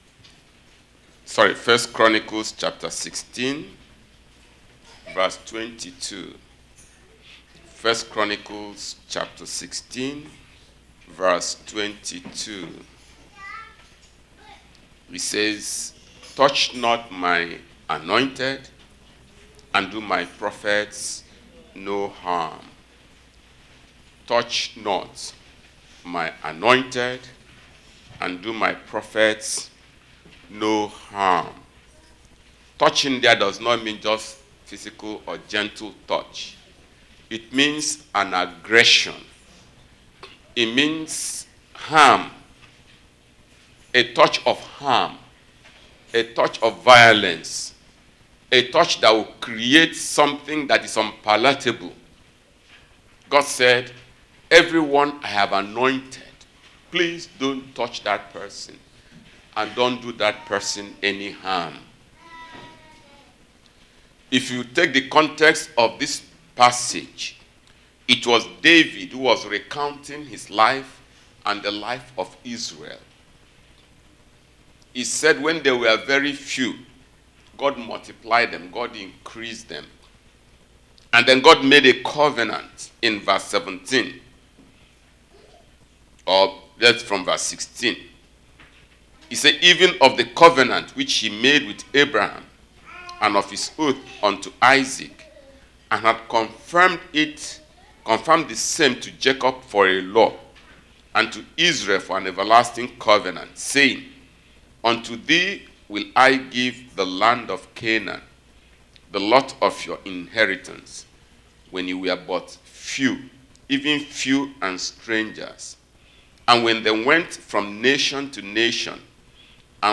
Our Sermon